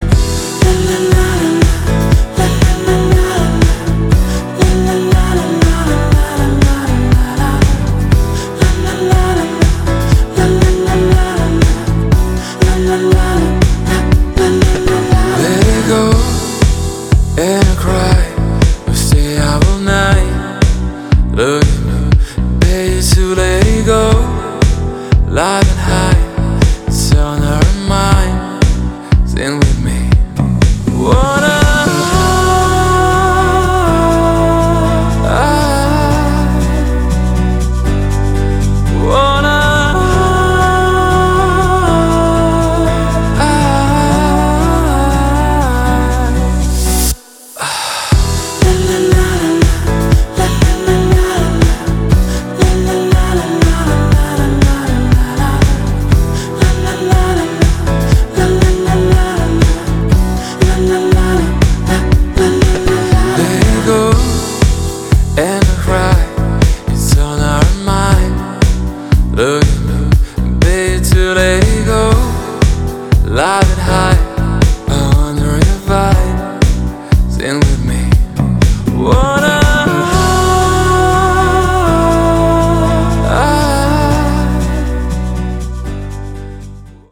• Качество: 320, Stereo
поп
красивый мужской голос
спокойные
красивая мелодия
релакс